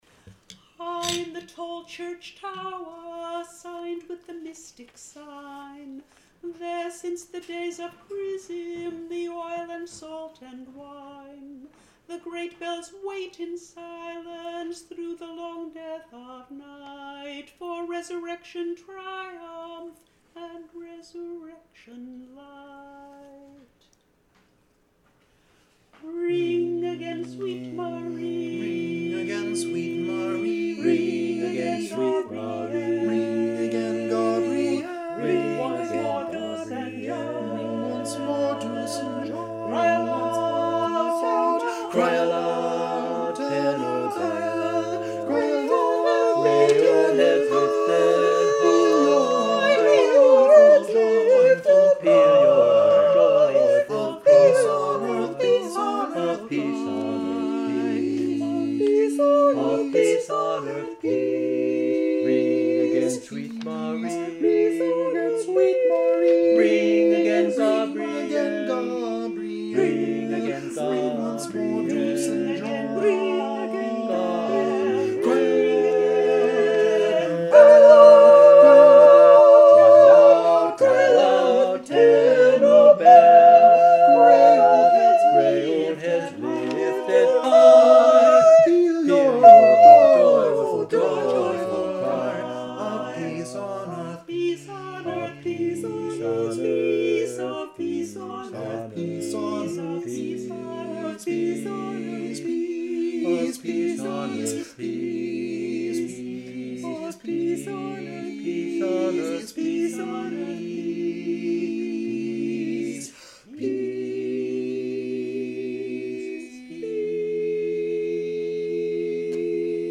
Number of voices: 4vv Voicing: SATB Genre: Secular, Art song
Language: English Instruments: A cappella